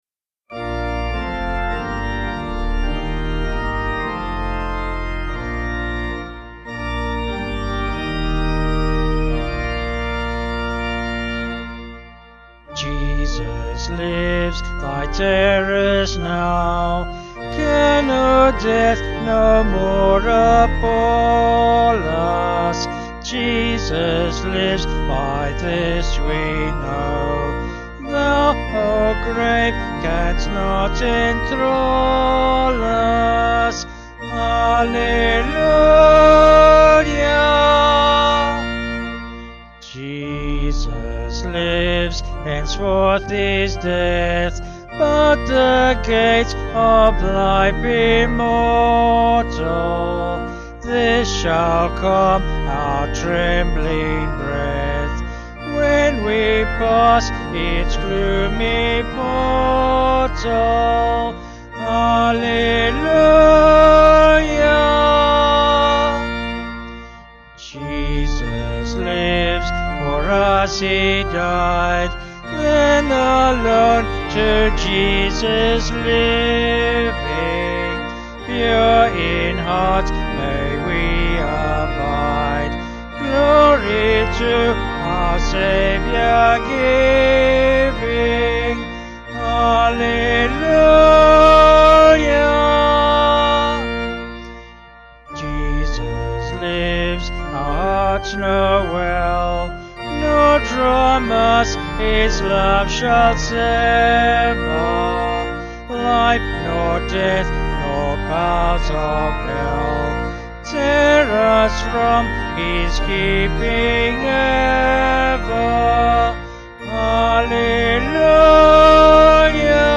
Vocals and Organ   254.7kb Sung Lyrics